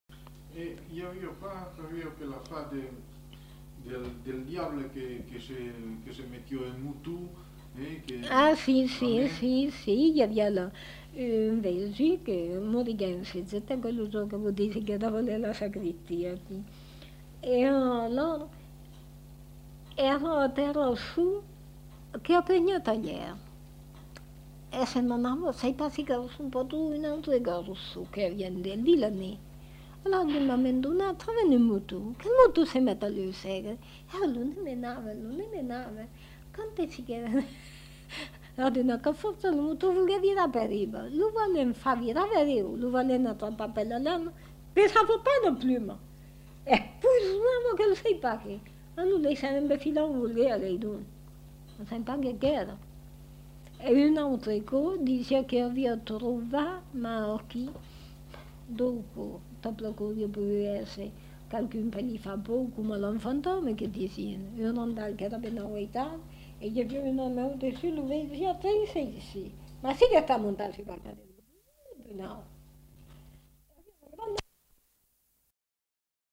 Aire culturelle : Périgord
Lieu : La Chapelle-Aubareil
Genre : conte-légende-récit
Effectif : 1
Type de voix : voix de femme
Production du son : parlé